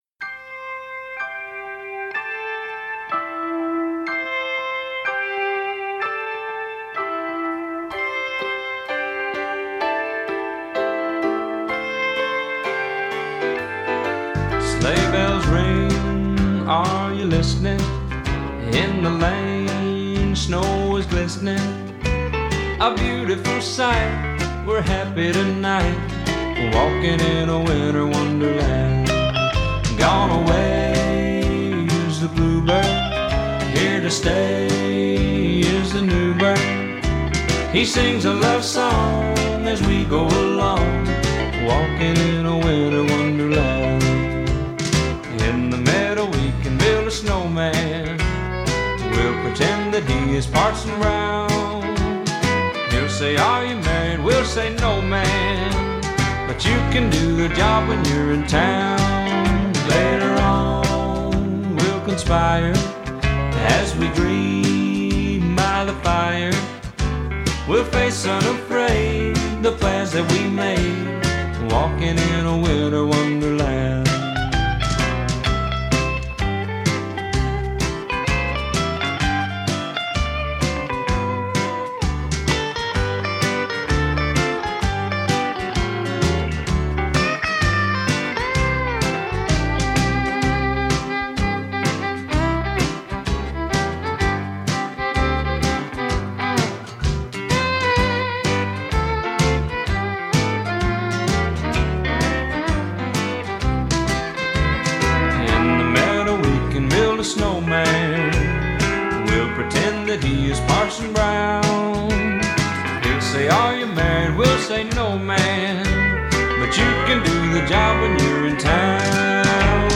Fait partie de Country Christmas music